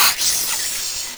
c_viper_dead.wav